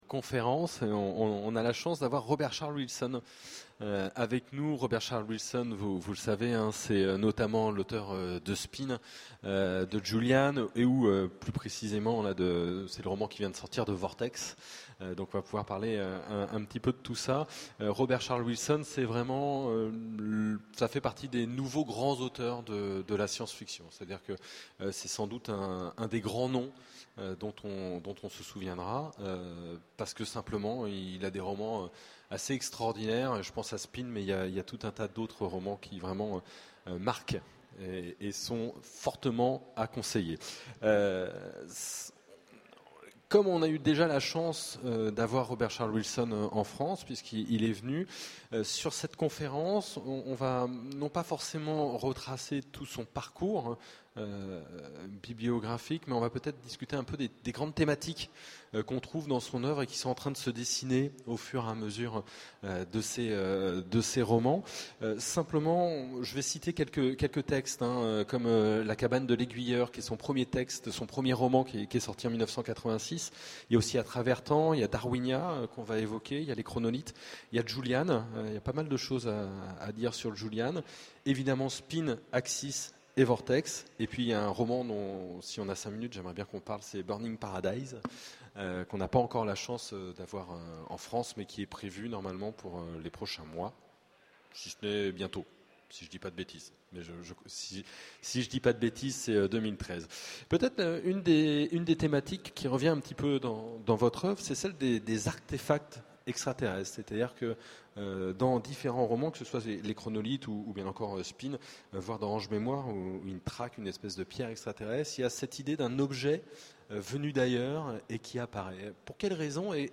Utopiales 12 : Conférence Rencontre avec Robert Charles Wilson
Conférence